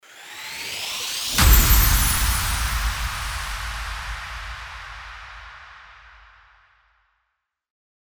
FX-1562-CHIMED-RISING-IMPACT
FX-1562-CHIMED-RISING-IMPACT.mp3